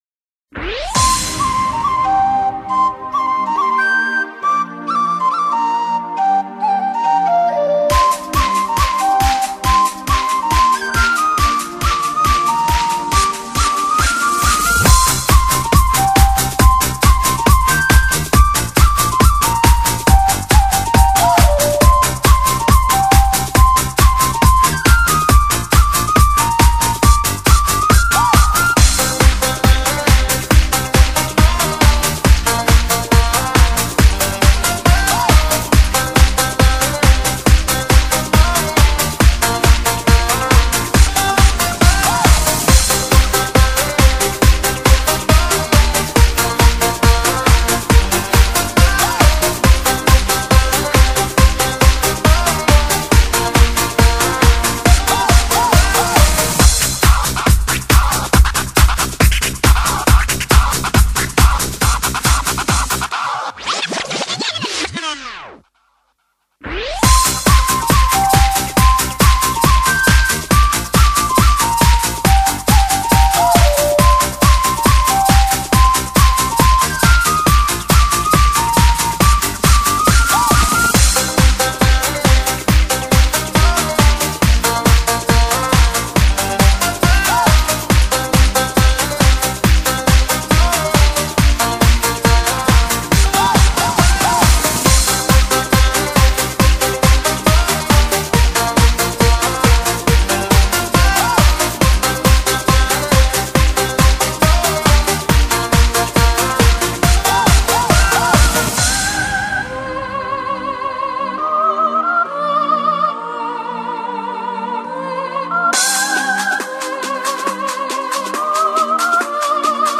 类型：Trance